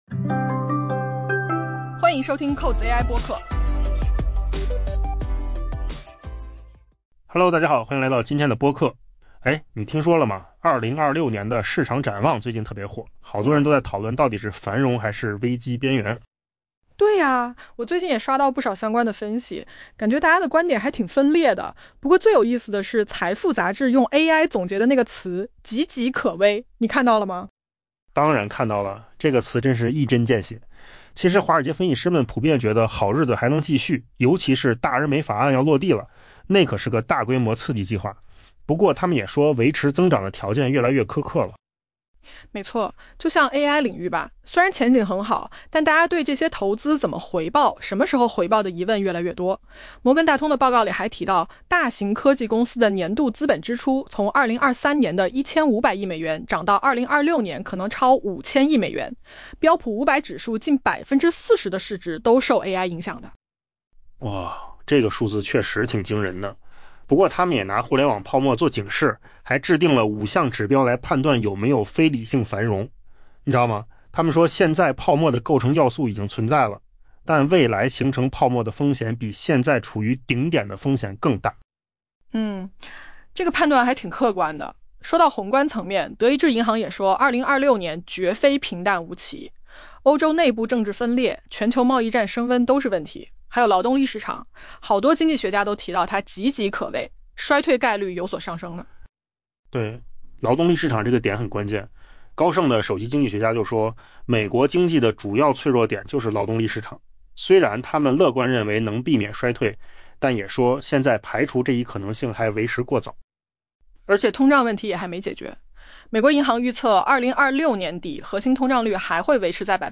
AI 播客：换个方式听新闻 下载 mp3 音频由扣子空间生成 在 2026 年即将到来之际，市场整体呈乐观态势。